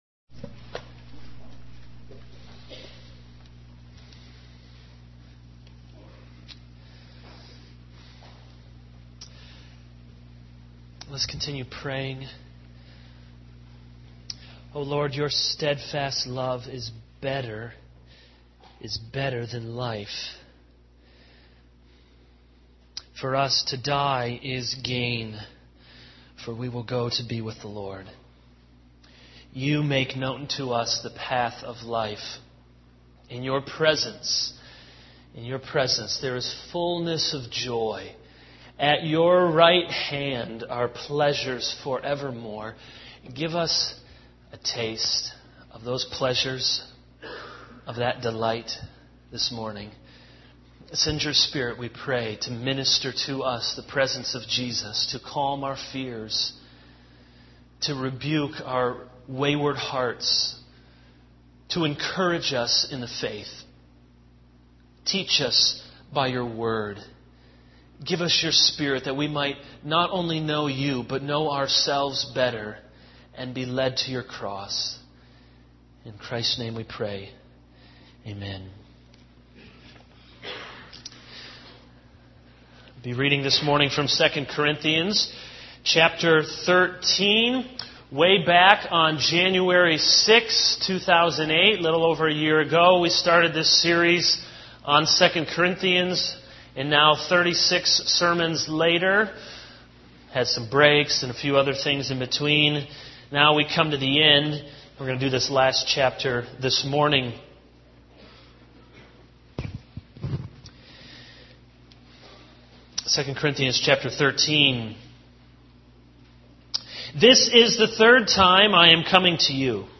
This is a sermon on 2 Corinthians 13:1-14.